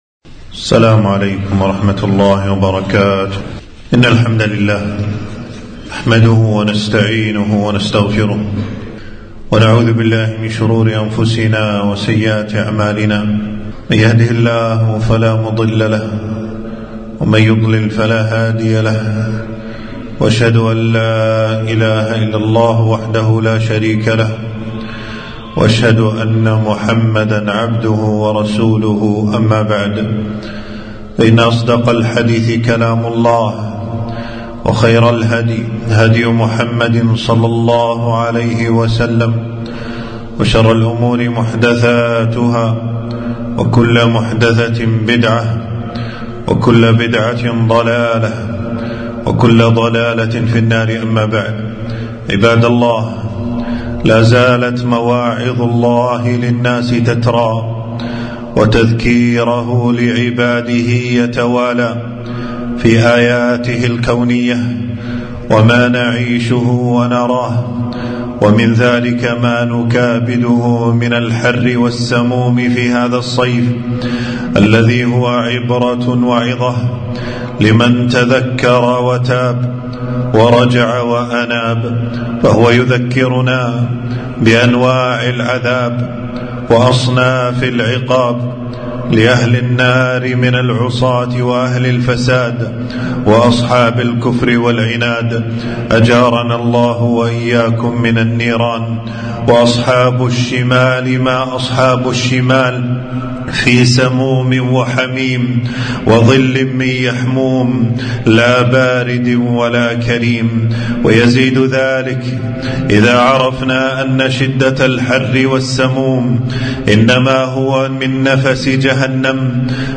خطبة - حر الصيف من سموم جهنم